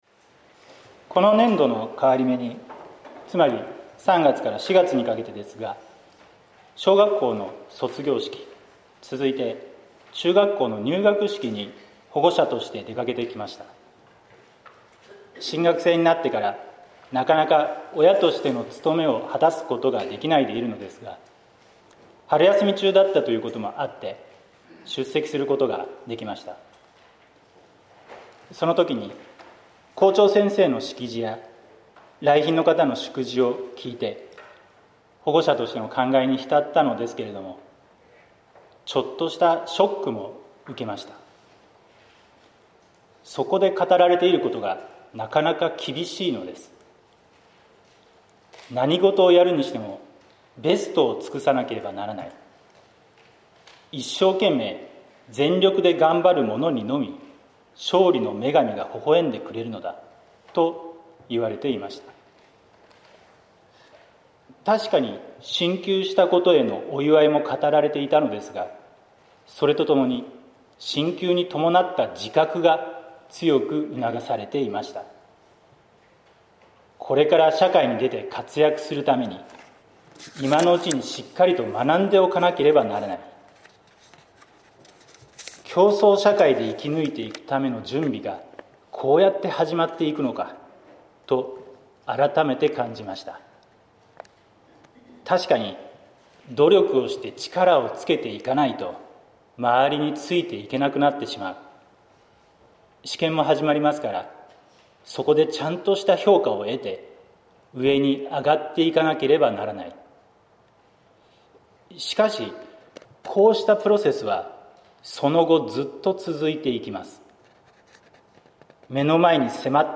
説教「優等生ではなくても」（音声版） | 日本福音ルーテル市ヶ谷教会